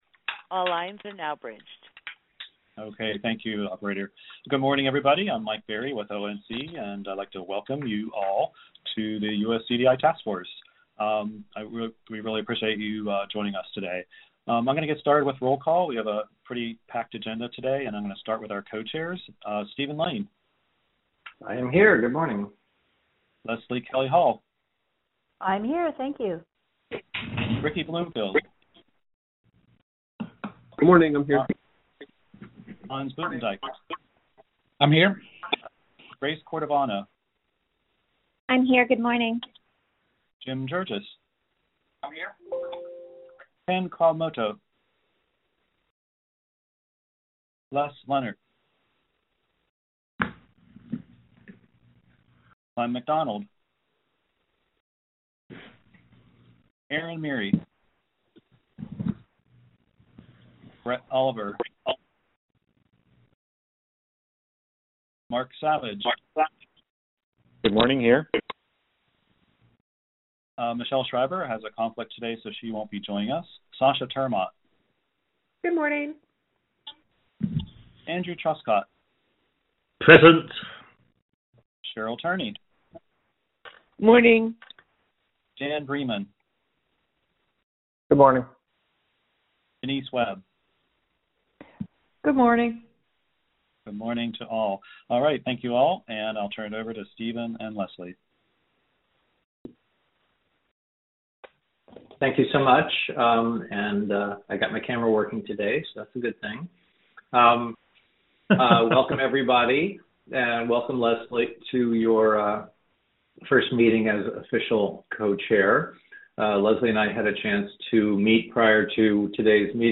U.S. Core Data for Interoperability (USCDI) Task Force Meeting Audio 3-2-2021